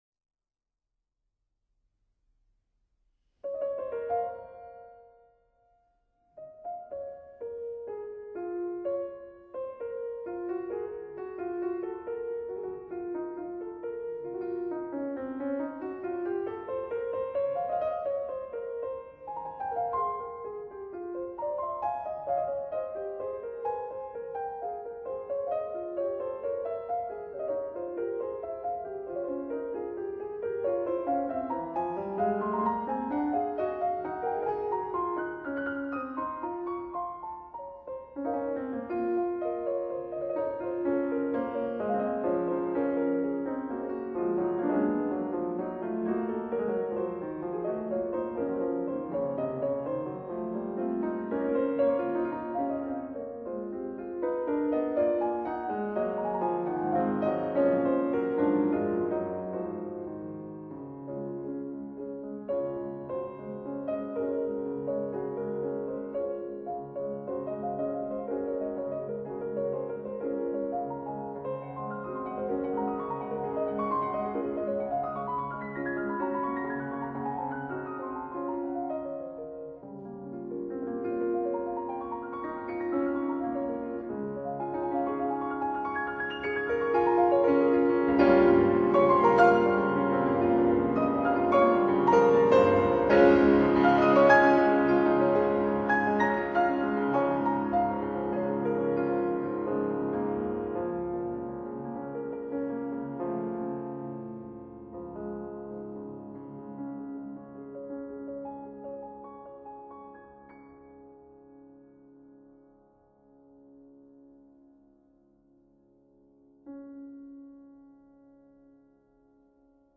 鋼琴曲輯
piano